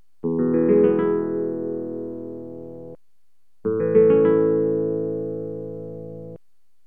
synthesised guitar sounds.(594 KB, .wav format).
The output of the model represents the sound of a plucked note as heard by a listener at a given distance in front of the guitar.
chords.wav